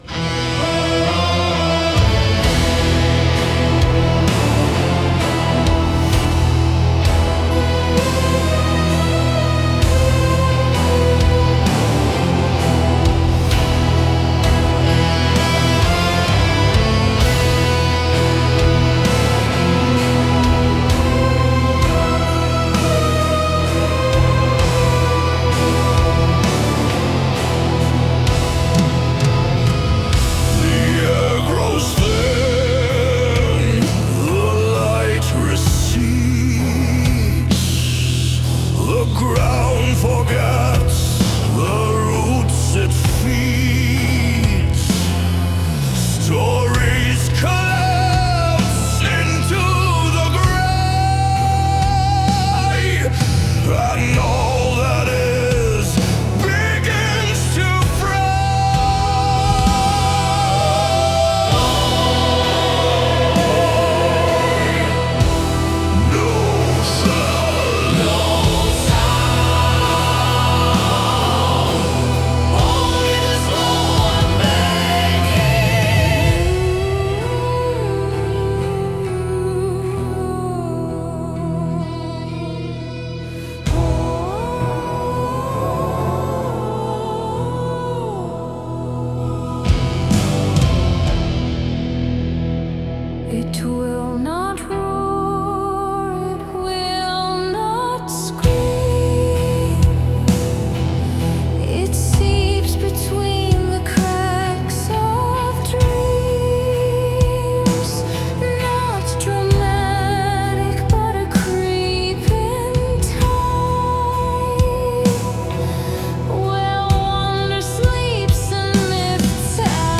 Fantasy Metal
a soaring, thunderous concept album